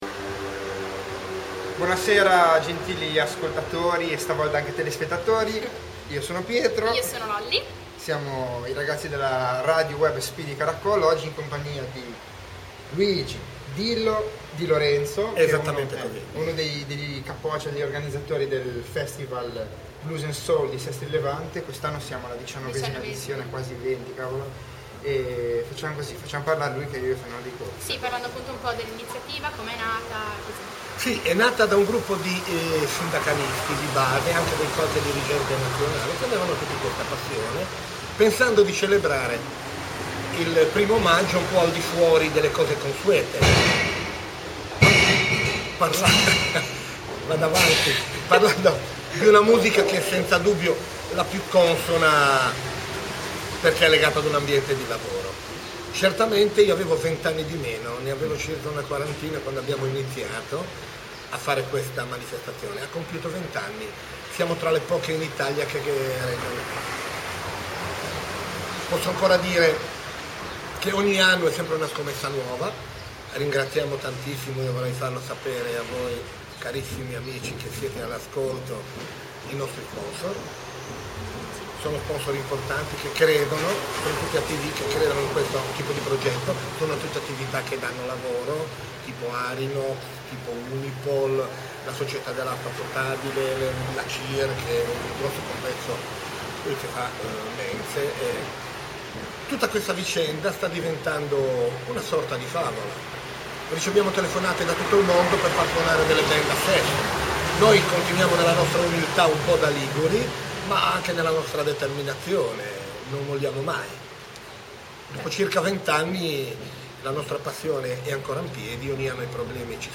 Intervista a Luigi "Dillo" DiLorenzo